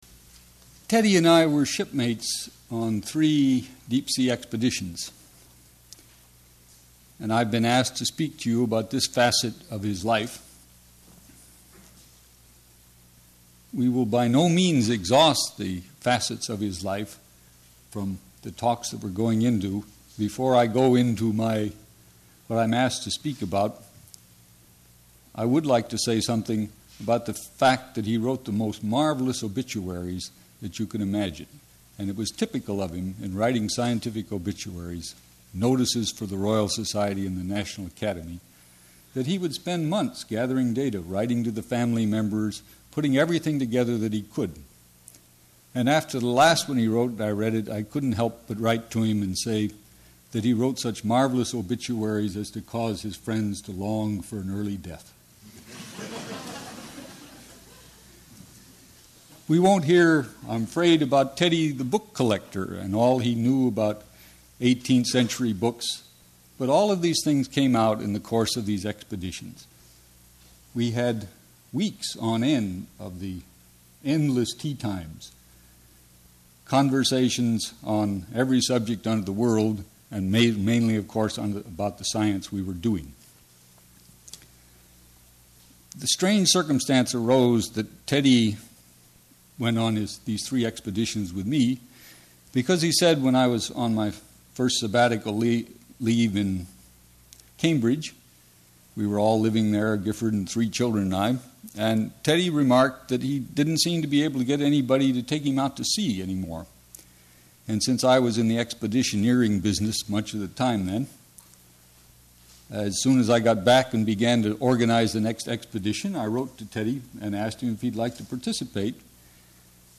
H. William Menard speaking on Edward Crisp Bullard